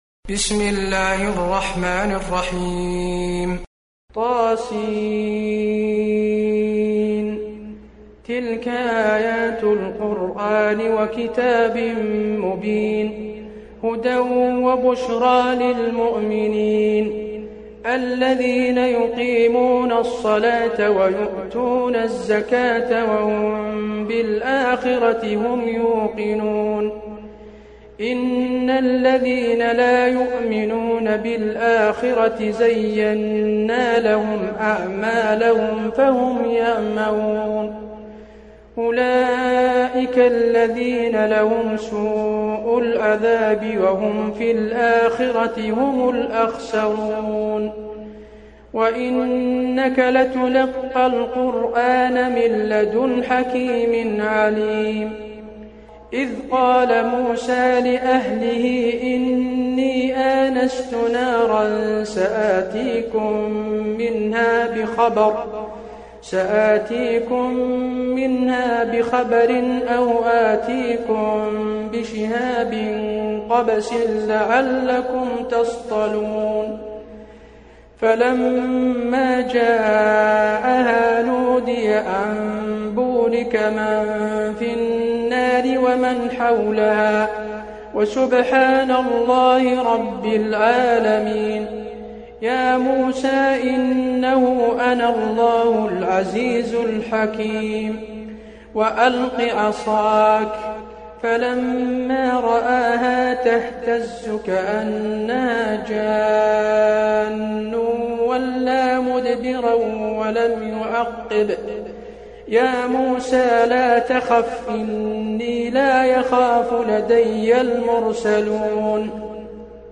المكان: المسجد النبوي النمل